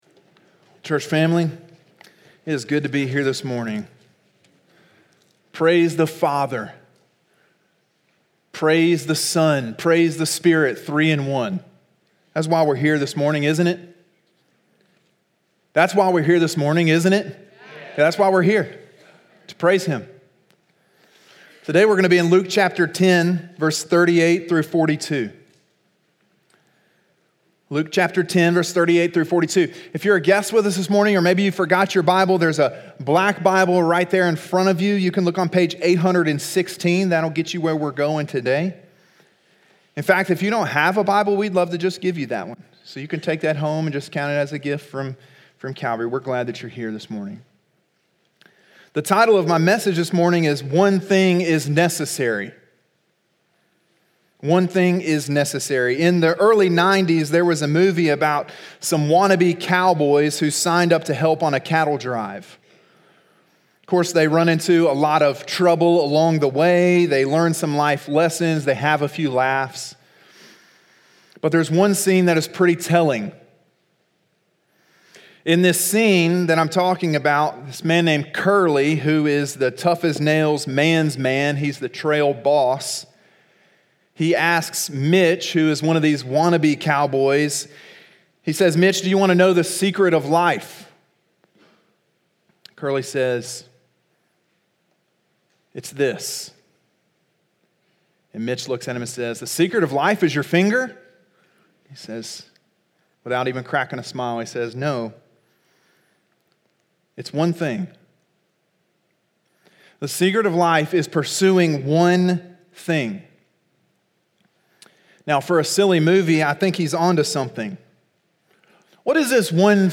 Sermon: “One Thing Is Necessary” (Luke 10:38-42) – Calvary Baptist Church